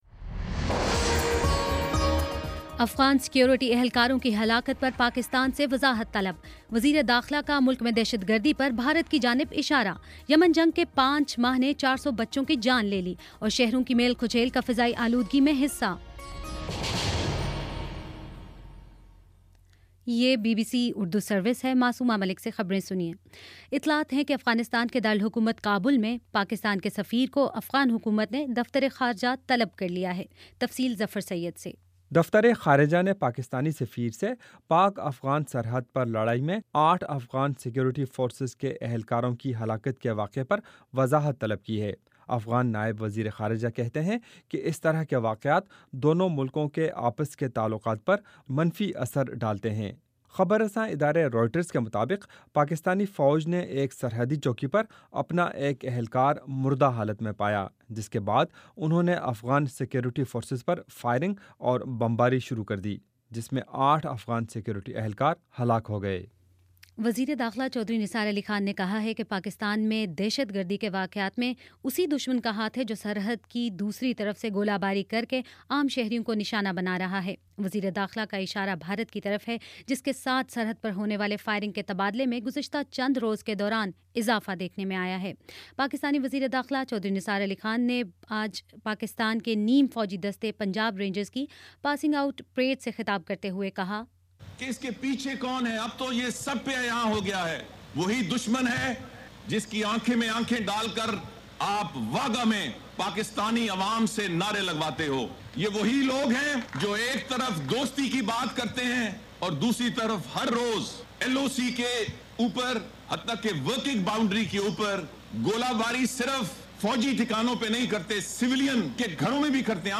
اگست 19: شام پانچ بجے کا نیوز بُلیٹن